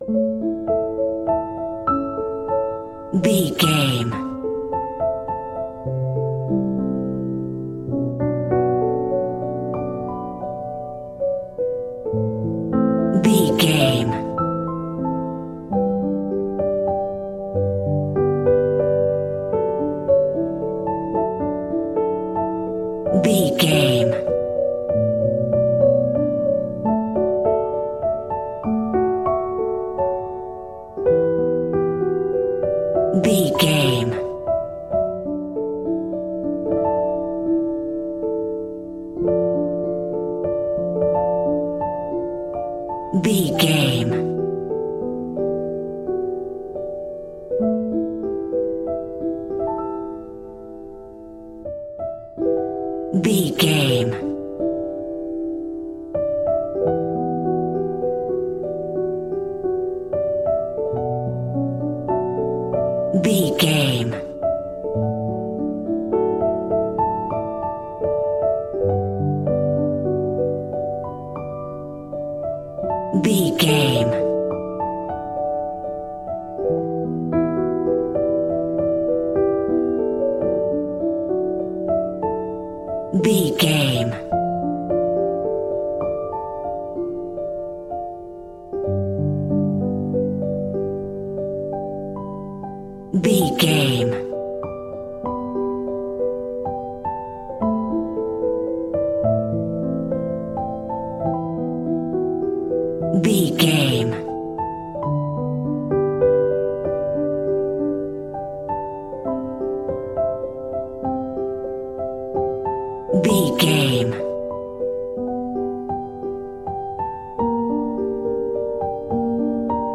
Ionian/Major
piano
contemplative
dreamy
haunting
ethereal
melancholy
tranquil